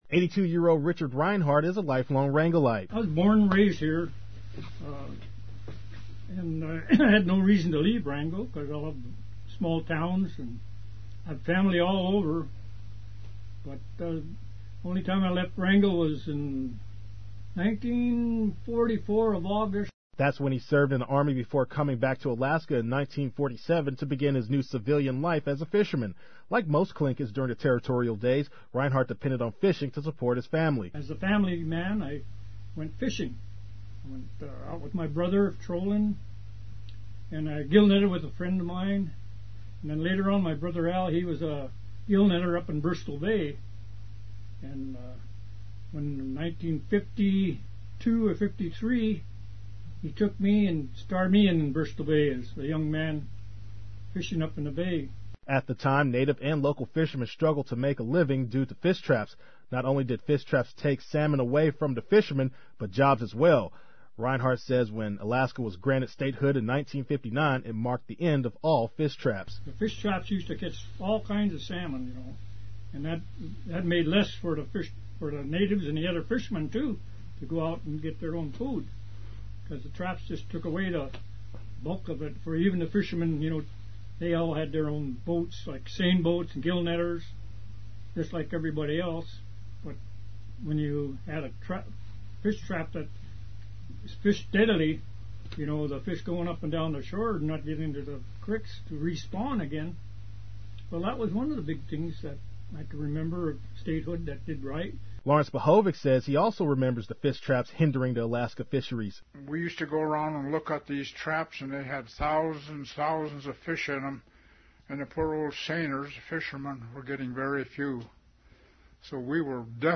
It's been 50 years since Alaska became a state. Two Wrangellites of Tlingit decent, who have been fishing in the Southeast region, reflect on the historic event and how it affected their lives.